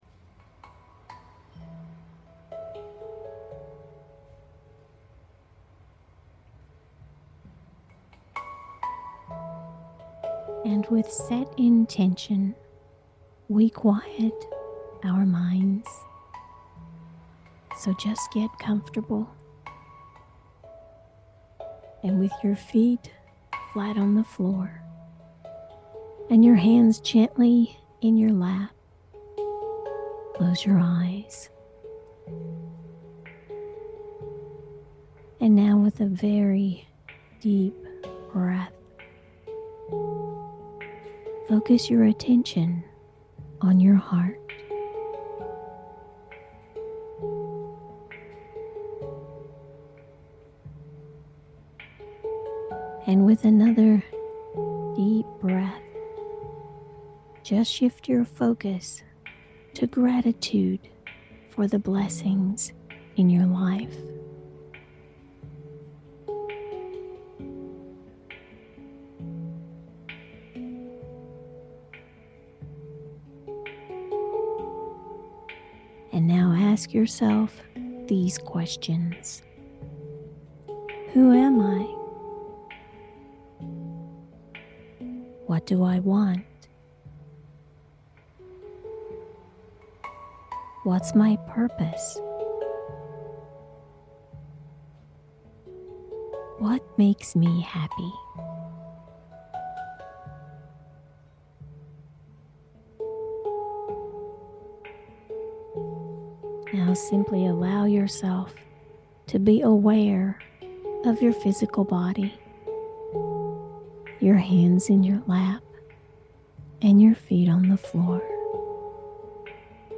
The process begins by focusing on the heart, then shifts to gratitude, then 4 questions are asked – don’t try to answer, simply ask, life will move you into the answers. Then you’ll be guided to become aware of your physical body.